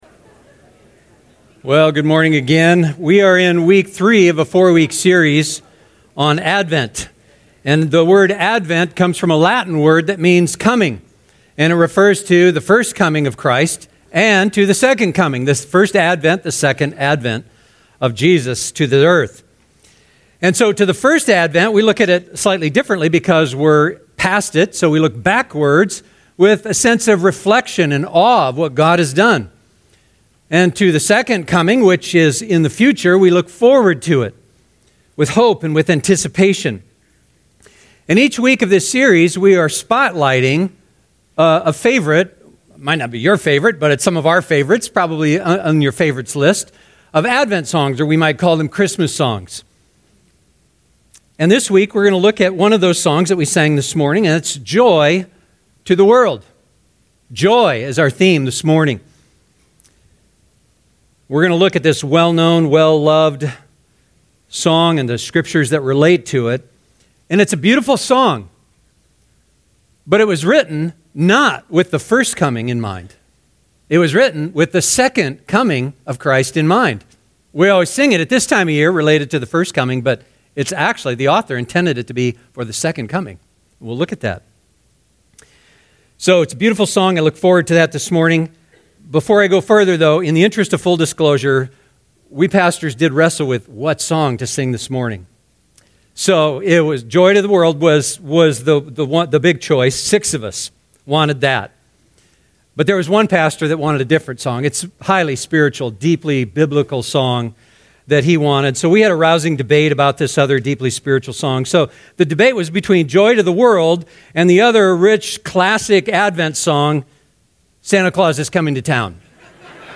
Advent 2019 SERMON POWERPOINT Sunday